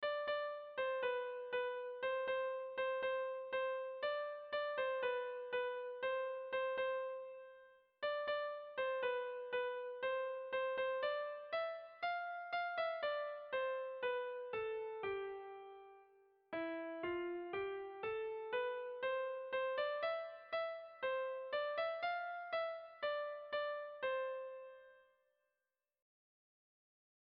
Irrizkoa
Seiko handia (hg) / Hiru puntuko handia (ip)
A1-A2-C